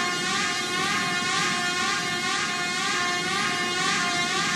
nostromoSiren.ogg